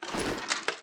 equip_iron4.ogg